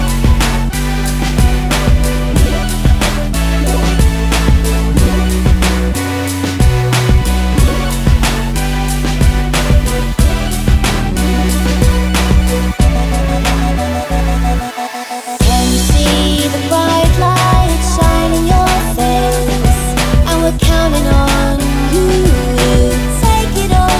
For Solo Male R'n'B / Hip Hop 3:39 Buy £1.50